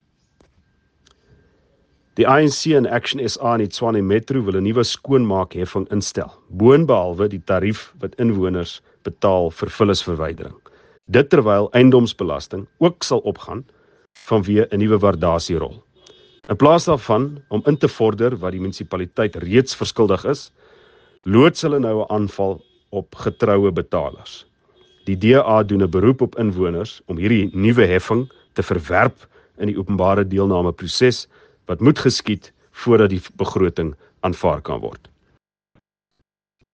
Note to Editors: Please find attached English and Afrikaans soundbites by Ald Cilliers Brink